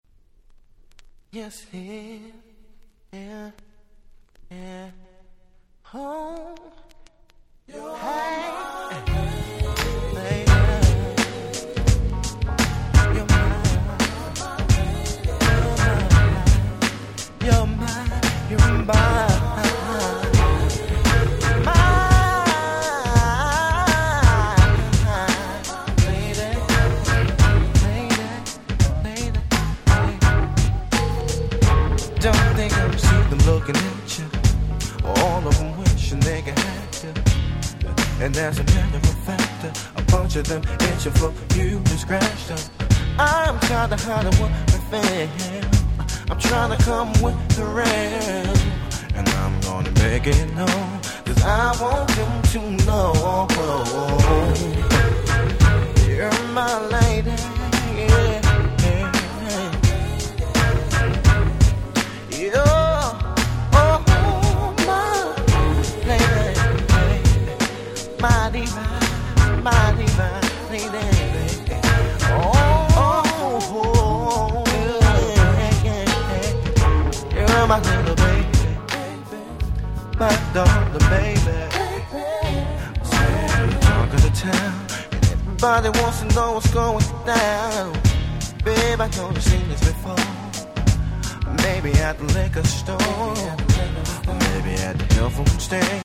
96' Super Hit R&B/Neo Soul !!
この気怠い感じがなんとも心地良いです。